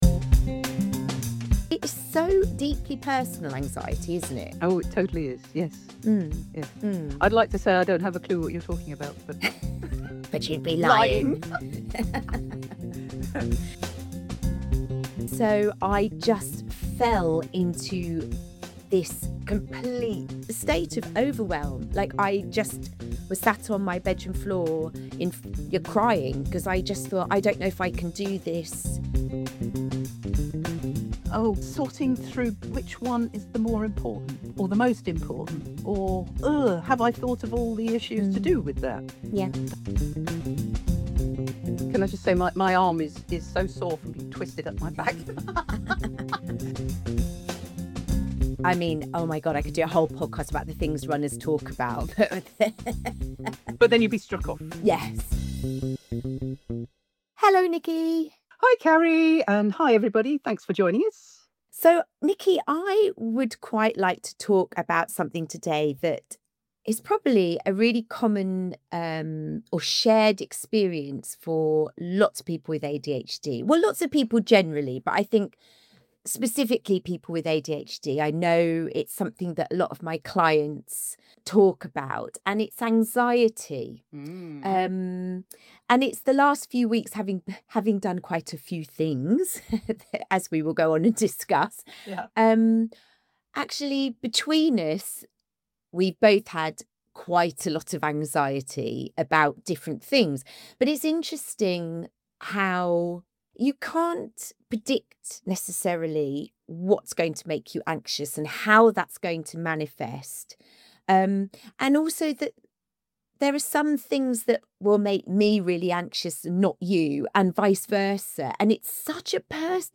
Welcome to The ADHD Connection – 2 ADHD coaches figuring it out together!